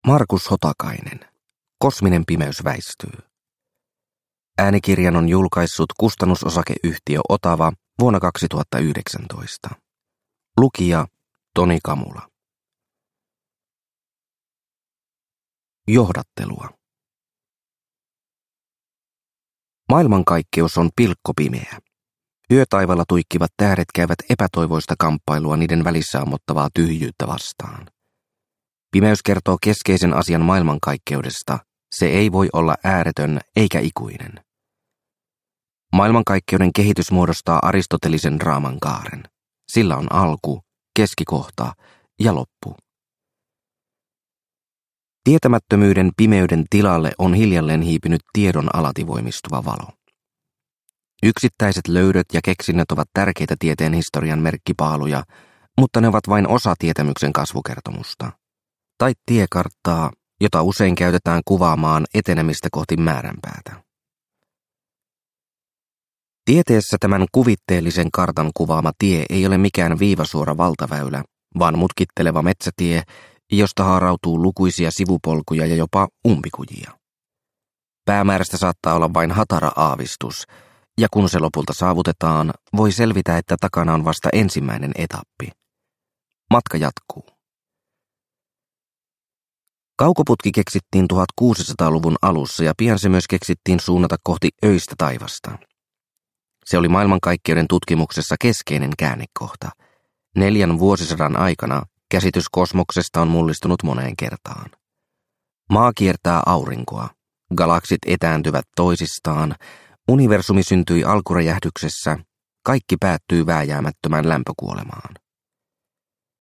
Kosminen pimeys väistyy – Ljudbok – Laddas ner